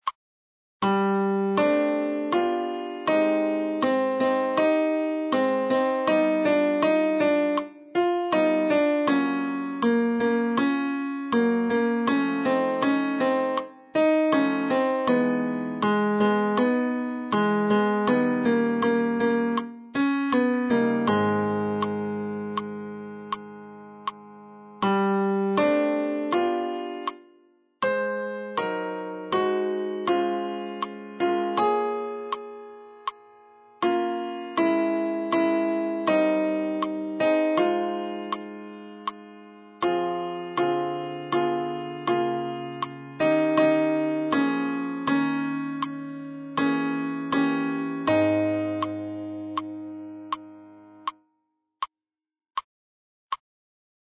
Bonjour – Alt